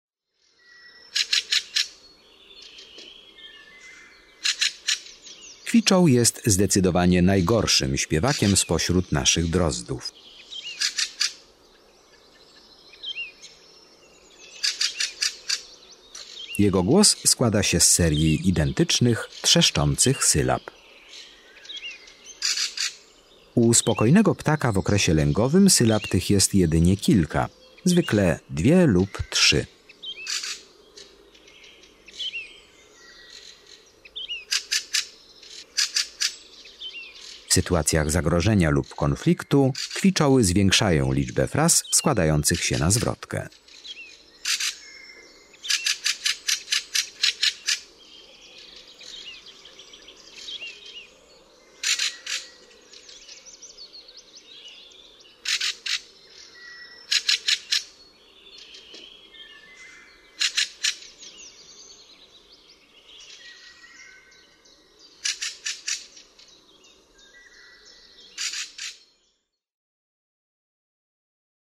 18 KWICZOŁ.mp3